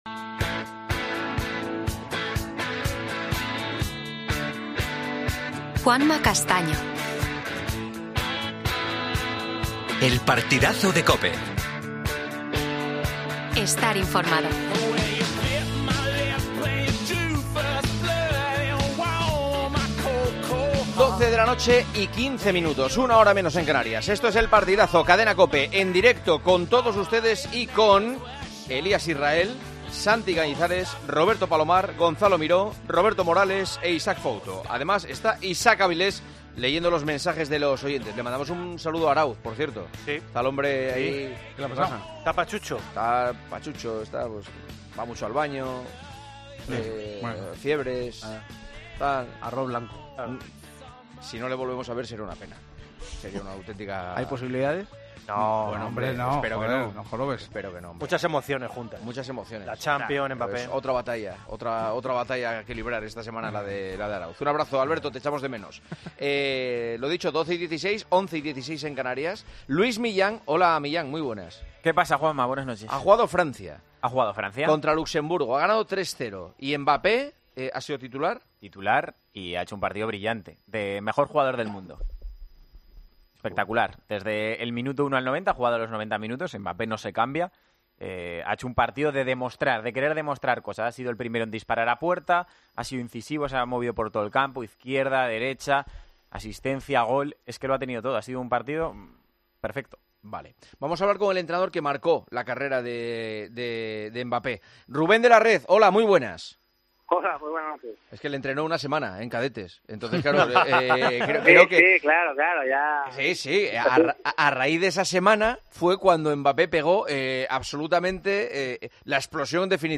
Entrevista a Rubén de la Red. El Madrid presenta su nueva camiseta.